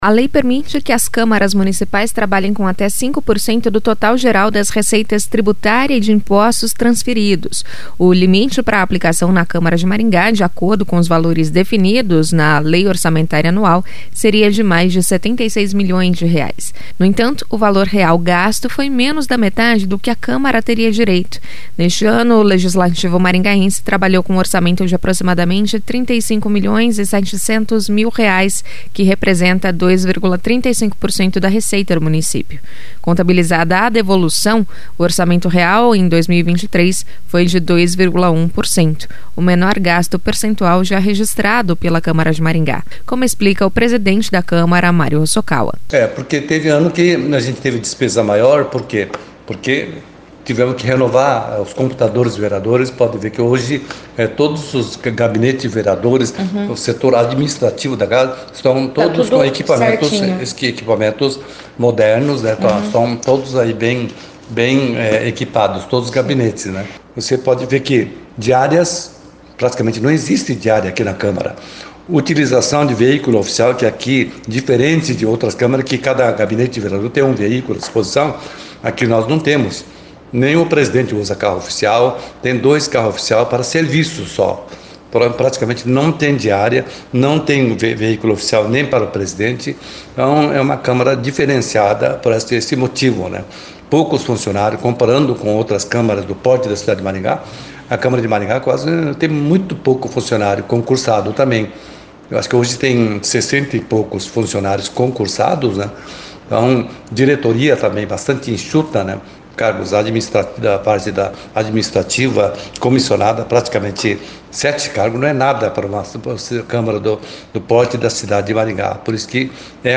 Contabilizada a devolução, o orçamento real em 2023 foi de 2,1%, o menor gasto percentual já registrado pela Câmara de Maringá, como explica o presidente da Câmara, Mário Hossokawa.
Os vereadores querem que o valor devolvido seja investido na saúde, a fim de diminuir a fila de espera para consultas especializadas, exames e cirurgias, diz Hossokawa.